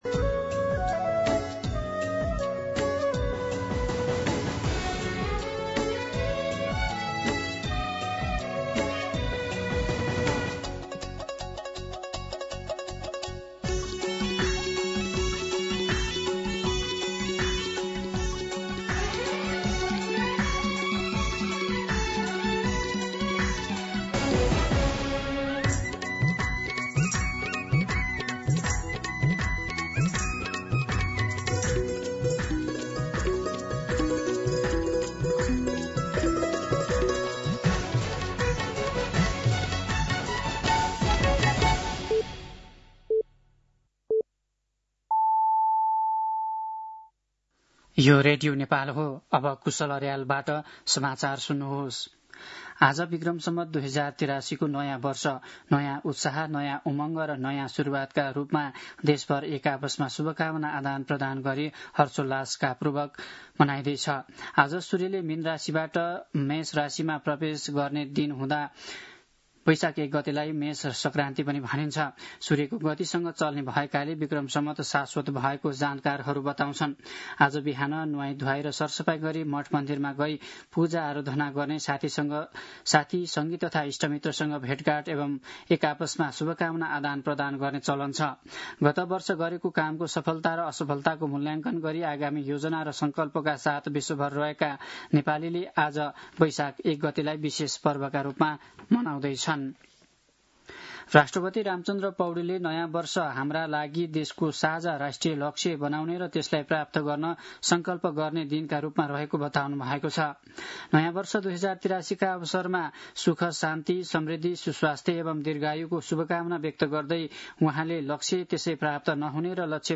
दिउँसो ४ बजेको नेपाली समाचार : १ वैशाख , २०८३
4-pm-News-01.mp3